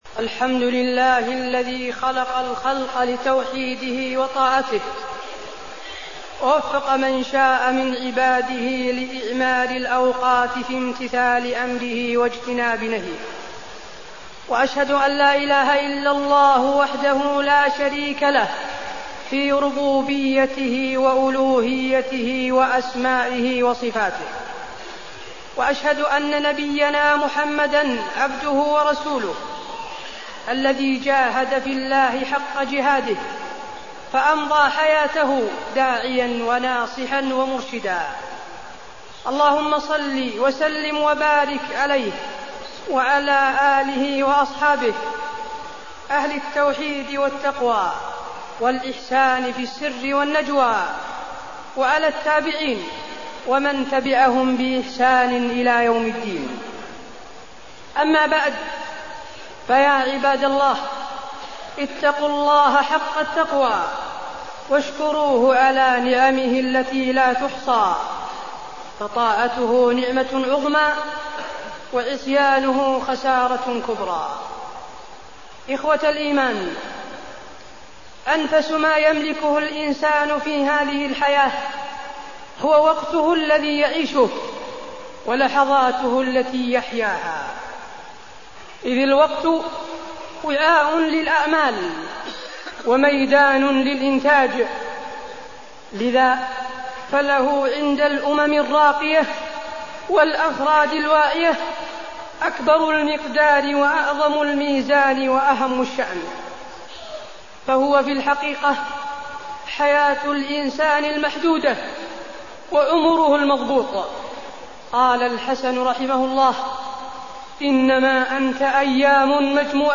تاريخ النشر ٢ ربيع الأول ١٤١٩ المكان: المسجد النبوي الشيخ: فضيلة الشيخ د. حسين بن عبدالعزيز آل الشيخ فضيلة الشيخ د. حسين بن عبدالعزيز آل الشيخ الوقت